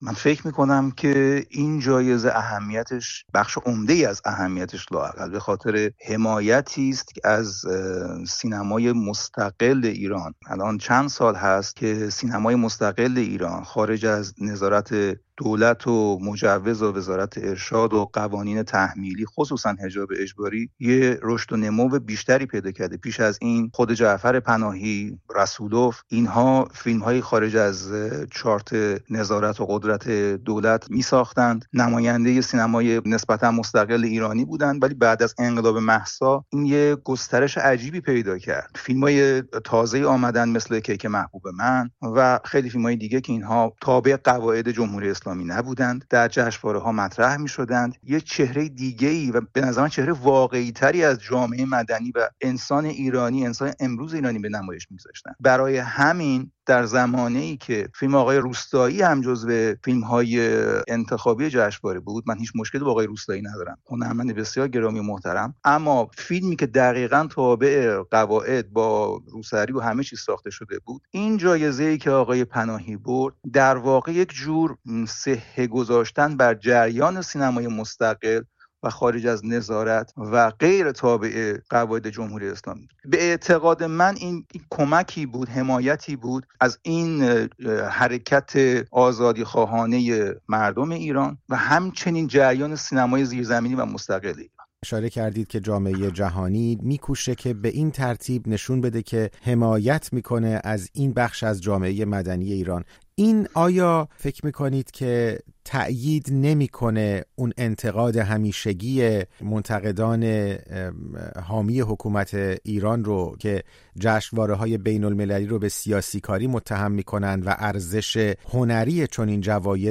مانا نیستانی، کارتونیست ساکن فرانسه و از امضاکنندگان این نامه، در گفت‌وگو با رادیو فردا از اهمیت اعطای نخل طلای کن به جعفر پناهی گفته است.